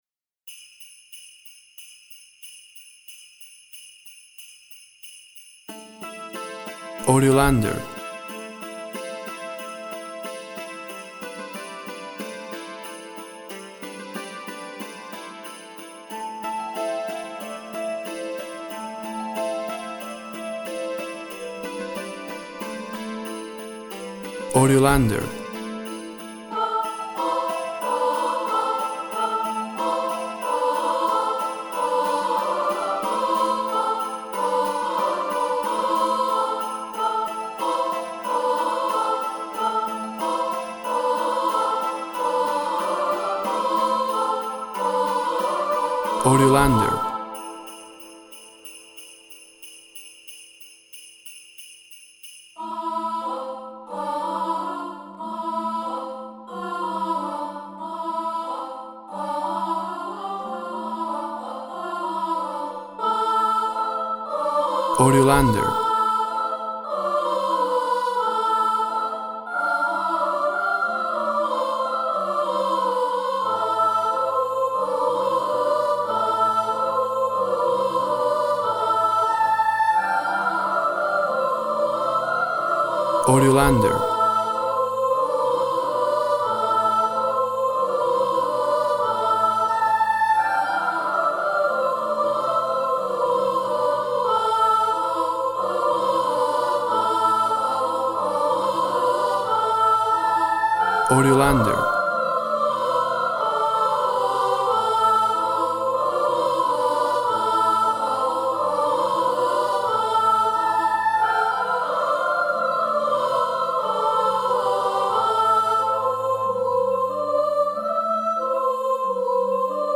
Tempo (BPM) 90